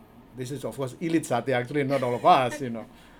S1 = Indonesian male S2 = Malaysian female Context: S1 is discussing the kinds of satay one might offer to guests in Indonesia, particularly Ponorogo satay, which is regarded as something of a special treat.
Intended Words: elite Heard as: a leet